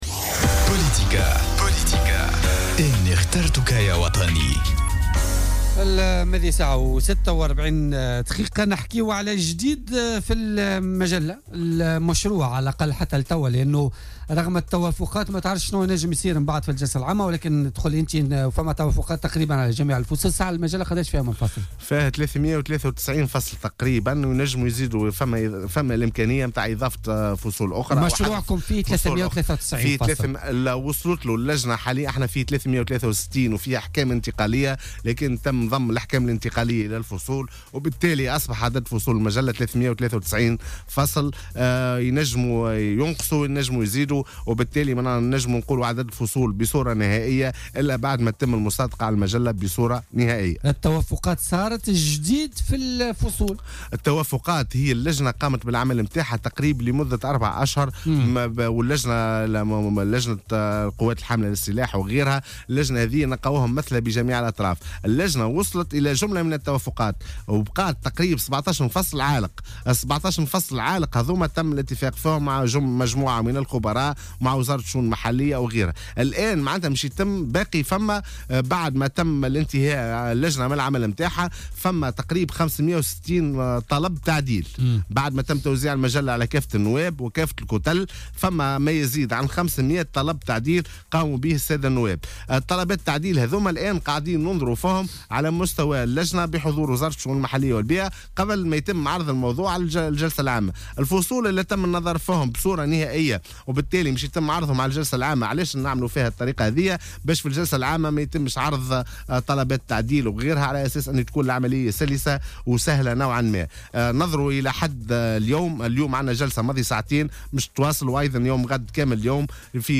وأوضح ضيف "بوليتيكا" اليوم الخميس، أن لجنة تنظيم الإدارة وشؤون القوات الحاملة للسلاح بالبرلمان، توصلت إلى توافقات بشأن جميع الفصول باستثناء 17 فصلا مازالت عالقة.